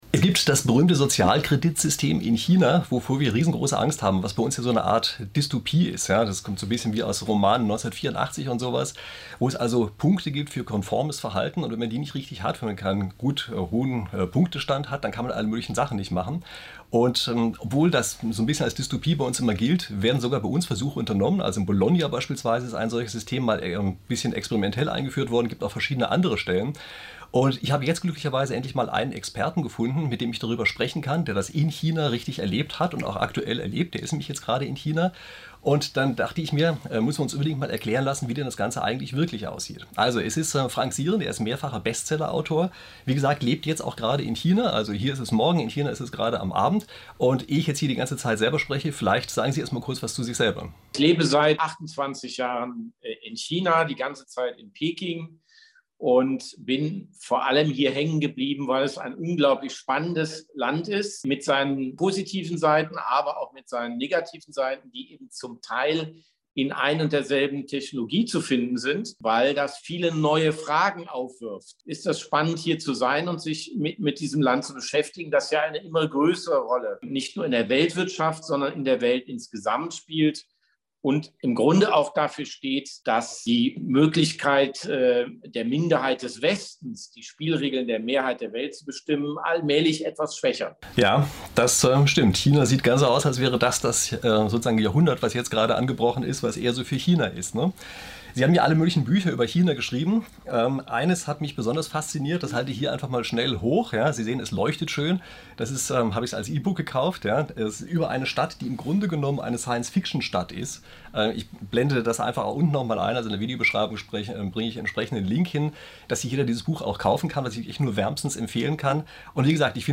163. Sozialkredit-System: China-Experte Frank Sieren im Interview